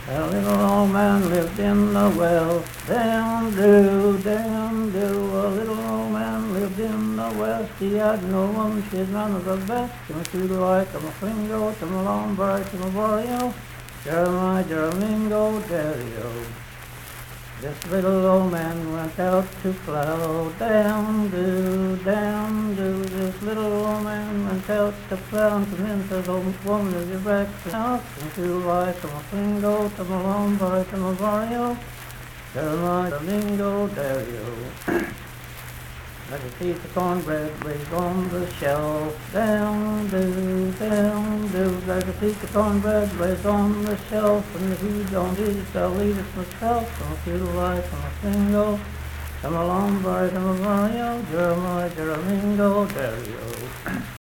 Unaccompanied vocal music
Verse-refrain 3(6w/R).
Voice (sung)
Marion County (W. Va.), Mannington (W. Va.)